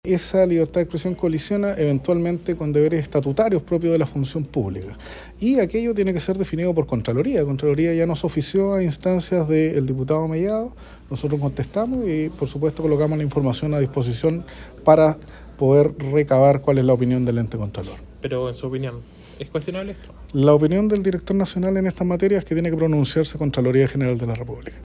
En una actividad en que no se invitó a prensa y tras varios intentos fallidos, finalmente el director nacional de la Conadi, Alvaro Morales, se refirió a la polémica afirmando que la libertad de expresión puede colisionar con elementos de la función pública, “y aquello tiene que ser definido por Contraloría”.